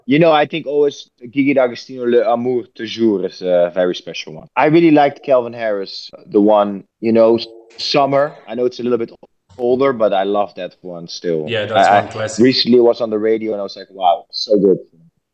Tom smo prilikom intervjuirali R3HAB-a koji je oduševljen suradnjom s Jasonom Derulom.